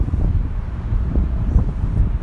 风声 " wind16
描述：风大风暴